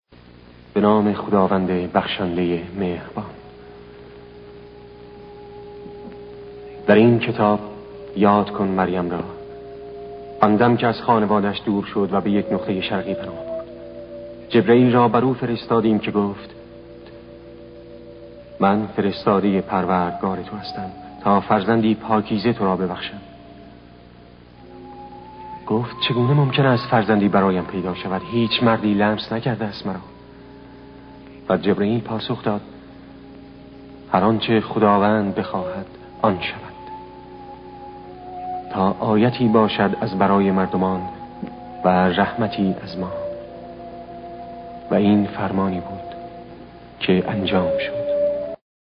صوت | خوانش کلام وحی
یکی از آثار به یادماندنی در کارنامه زنده‌یاد مقامی صداپیشگی به جای نقش زید و جعفر بن‌ ابیطالب در فیلم سینمایی محمد رسول ا... است.
نقش جعفر با آن دیالوگ‌های شنیدنی، با صدای مخملی مقامی تاثیرگذاری بیشتری داشت و بر جان مخاطبان نشست.